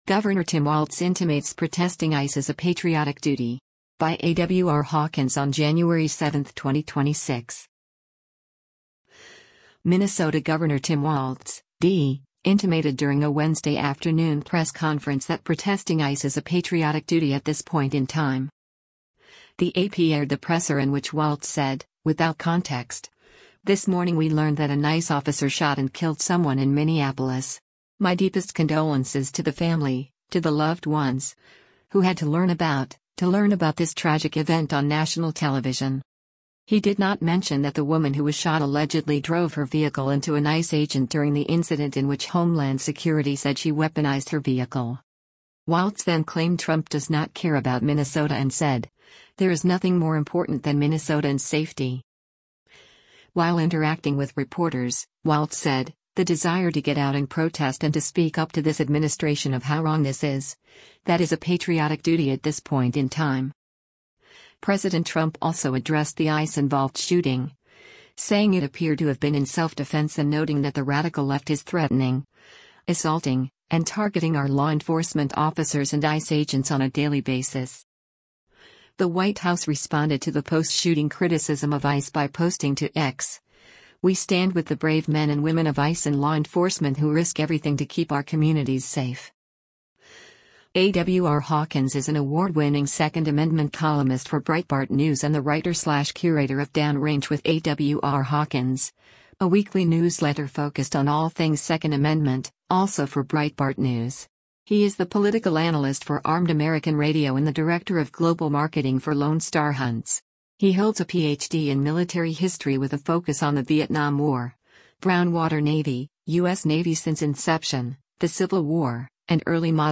Minnesota Gov. Tim Walz speaks during a press conference at the State Capitol building on
Minnesota Gov. Tim Walz (D) intimated during a Wednesday afternoon press conference that protesting ICE is “a patriotic duty at this point in time.”